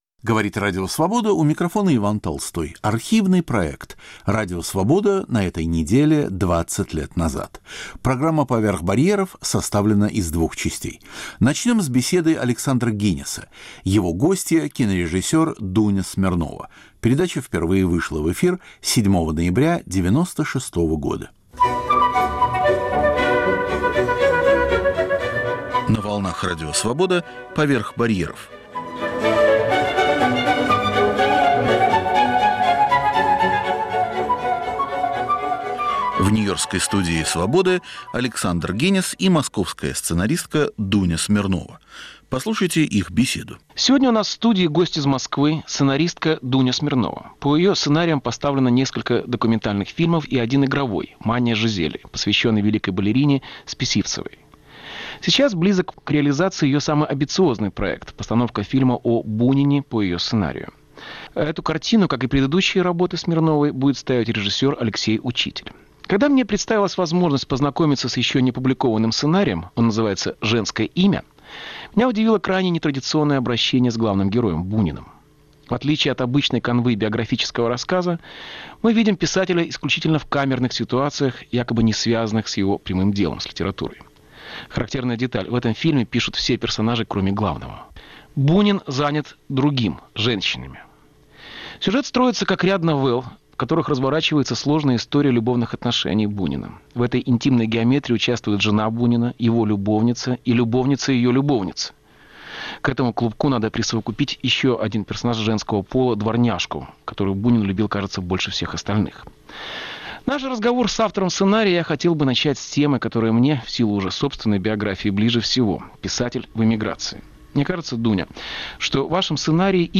Программа "Поверх барьеров". В первой части - кинорежиссер Дуня Смирнова в беседе с Александром Генисом.